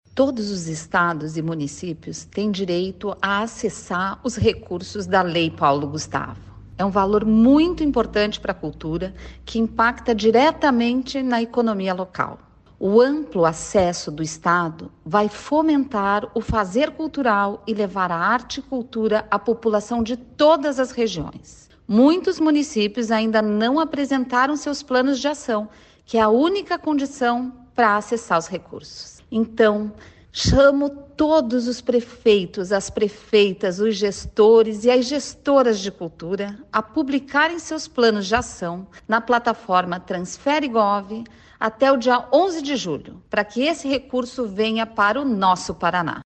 Sonora da secretária da Cultura, Luciana Casagrande, sobre o prazo para adesão de municípios à Lei Paulo Gustavo | Governo do Estado do Paraná